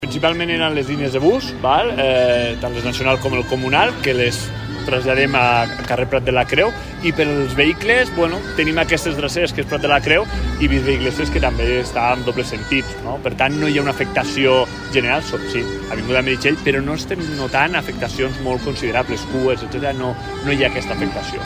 Pel que fa al trànsit, el cònsol major ha destacat que no s’han registrat incidències rellevants ni cues destacables.